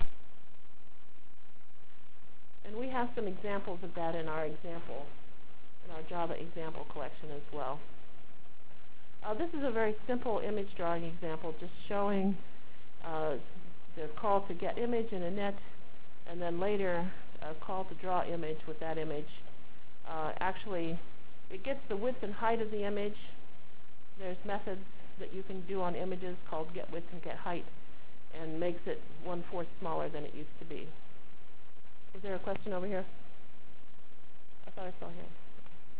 Delivered Lecture for Course CPS616